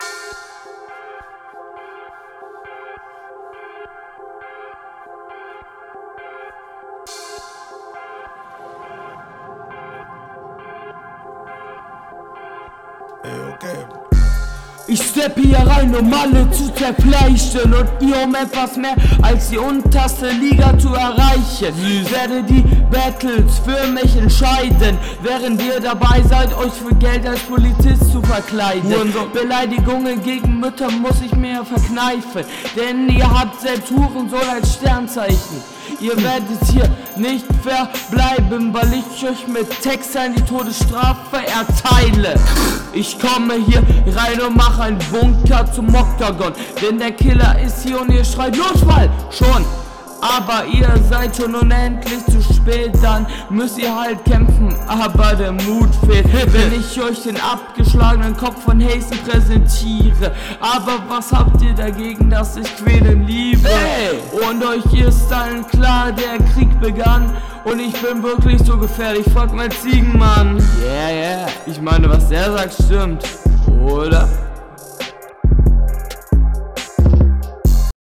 Battle Rap Bunker
Battlerapper aus Deutschland der ultra bock hat.